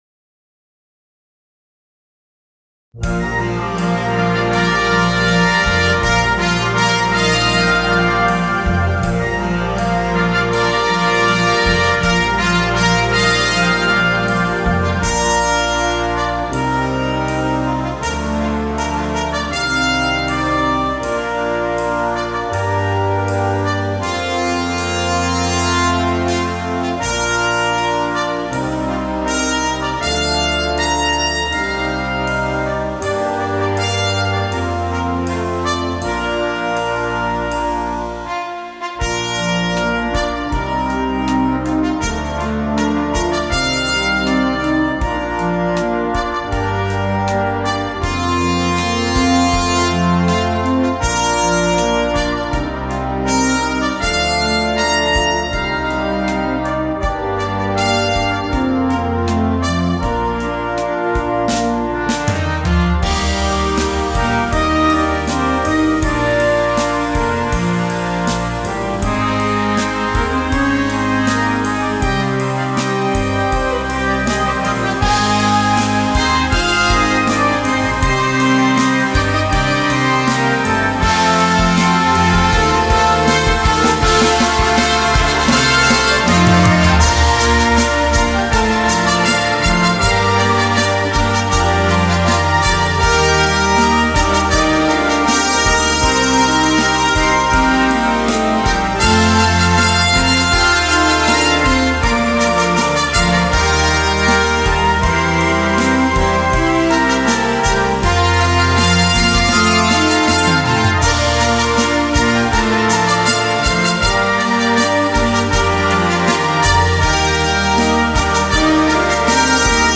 Flute
Trumpets 1-2
Euphonium
Tuba
Timpani
Glockenspiel